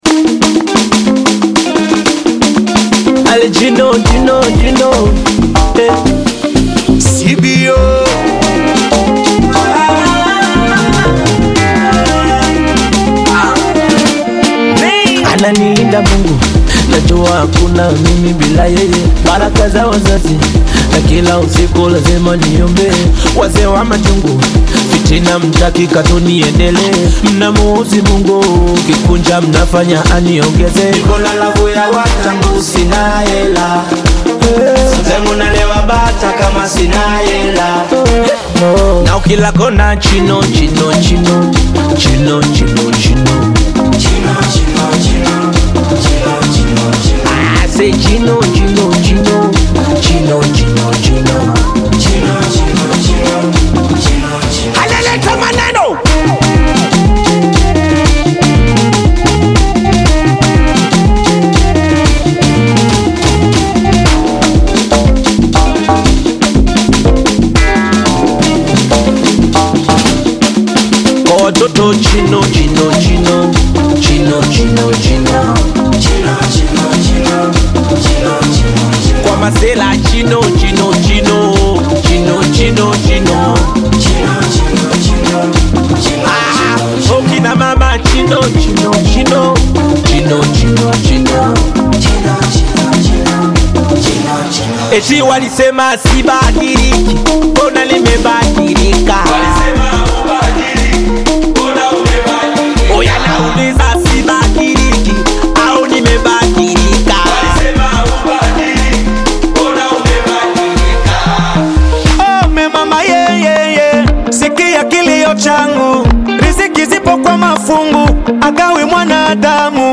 heartfelt Bongo Flava/Afro-Pop single
featuring smooth, emotive vocals